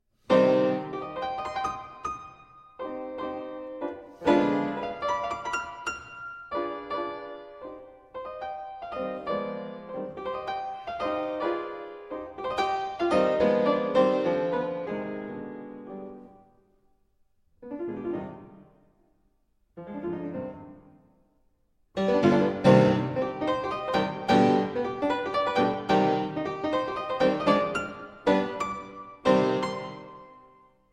Uitgevoerd door Alexei Lubimov, op een historisch instrument.